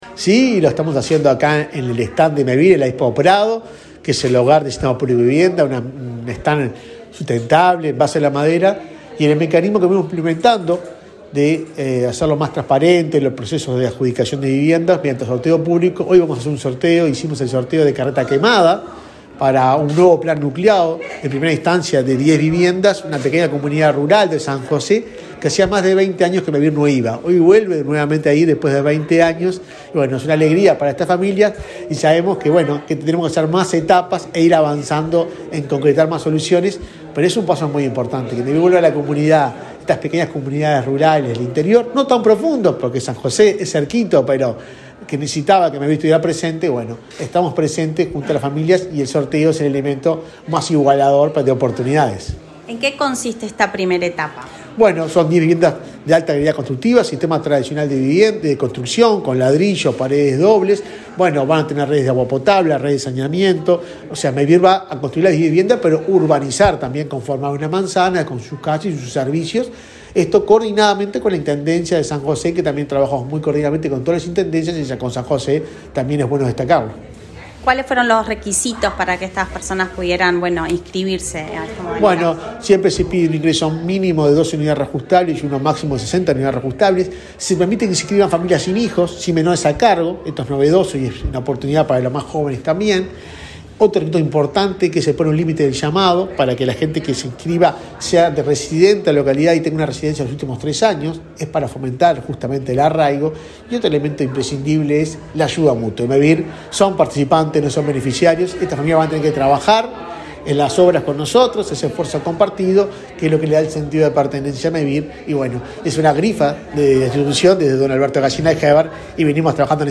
Entrevista al presidente de Mevir, Juan Pablo Delgado
Este miércoles 13 en la Expo Prado, el presidente de Mevir, Juan Pablo Delgado, dialogó con Comunicación Presidencial, luego de participar en un